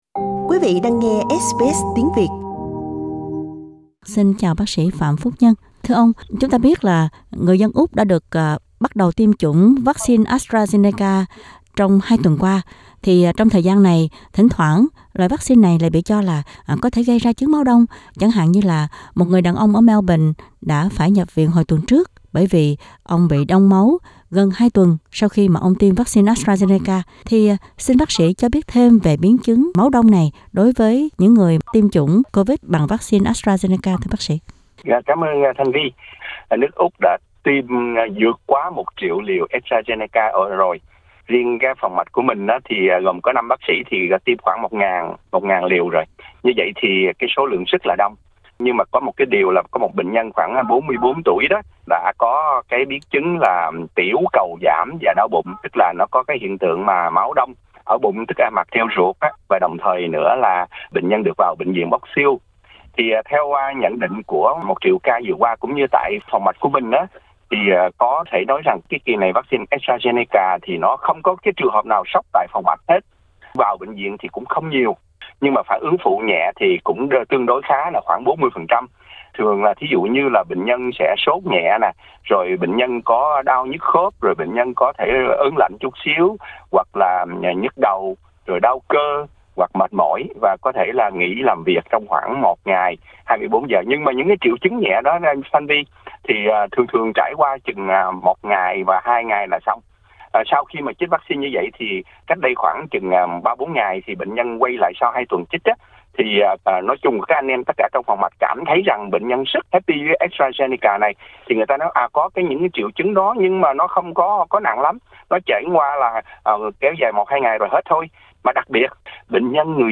Trong phần phỏng vấn đầu trang